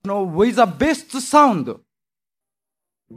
1976年2月、高田馬場BIGBOXで開催されたDJ大会。
▶ DJ音声①（オープニング）
①DJ開始一声-the-best-sound-1.mp3